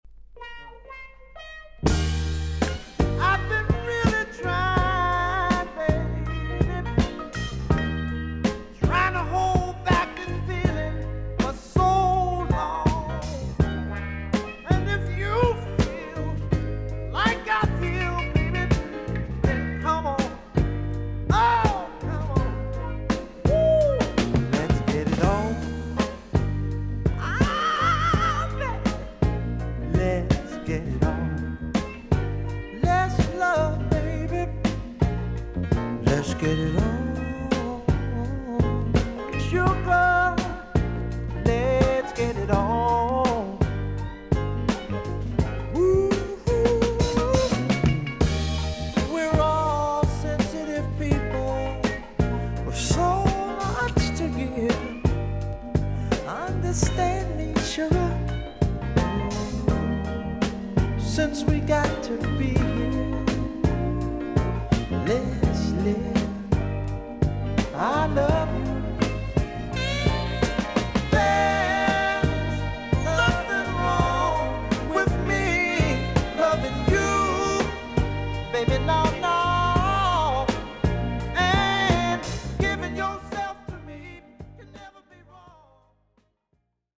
少々軽いパチノイズの箇所あり。クリアな音です。
R&B/ソウル・シンガー。愛についてセクシーに歌ったニュー・ソウル名盤。